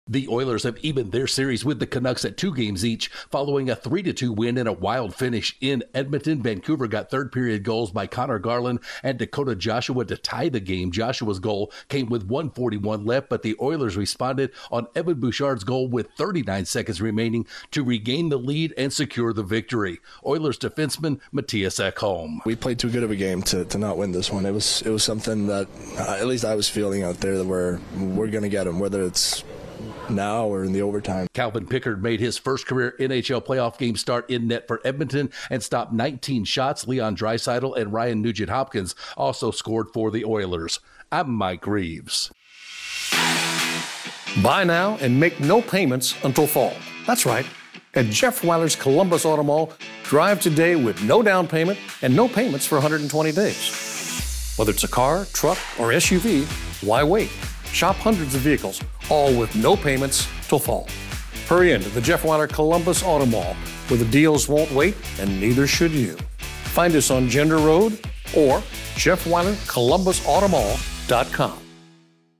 The Oilers picked up a big win in game four of their series against the Canucks. Correspondent